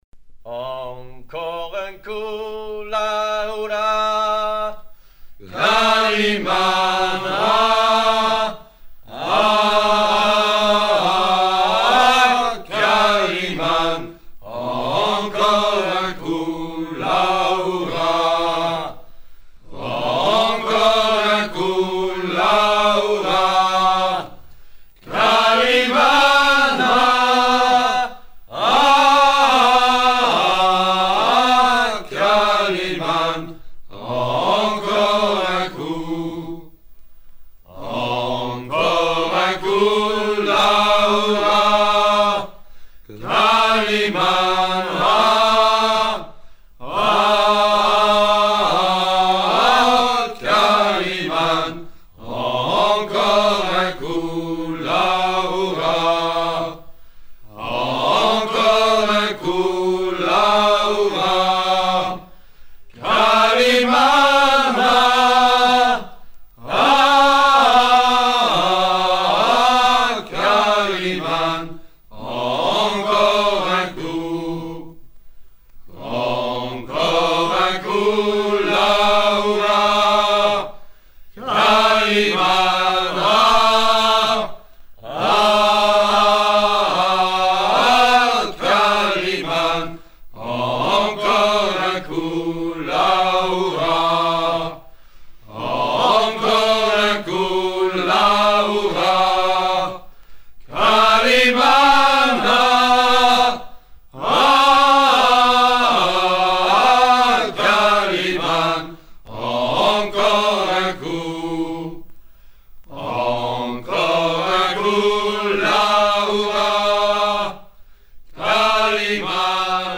chants brefs
gestuel : à déhaler
circonstance : maritimes